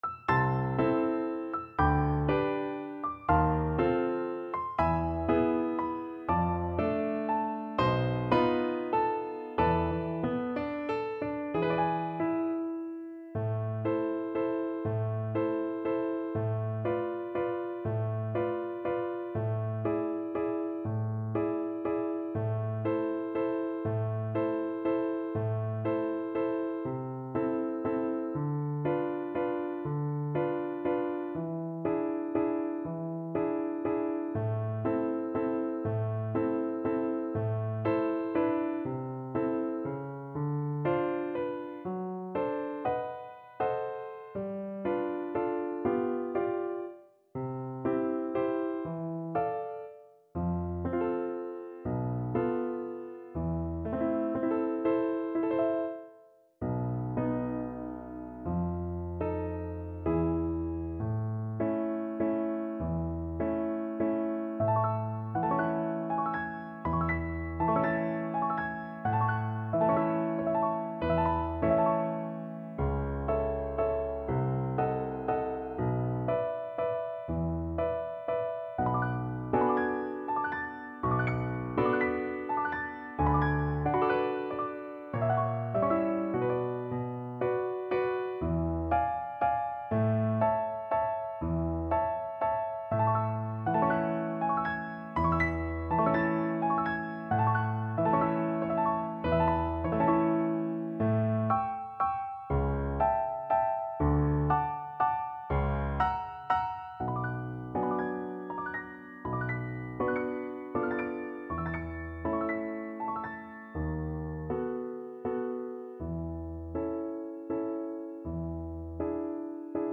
3/4 (View more 3/4 Music)
~ = 120 Lento
Classical (View more Classical Flute Music)